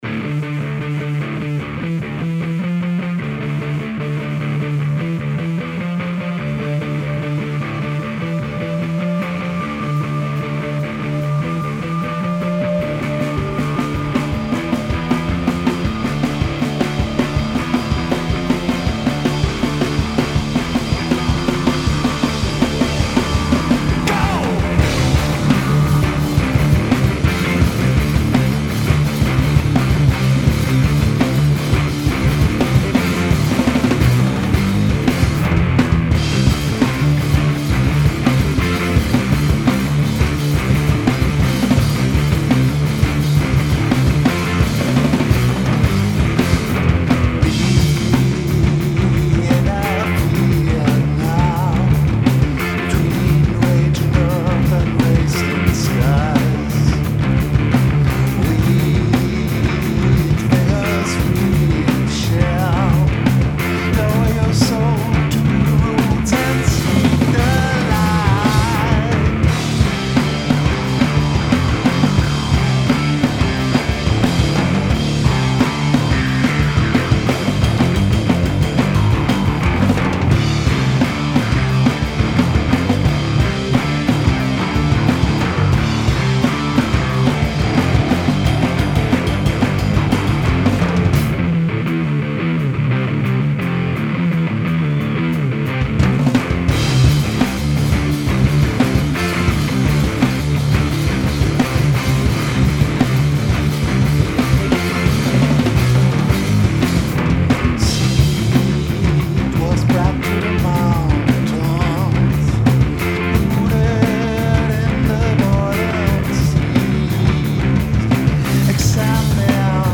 Live at Bad Bonn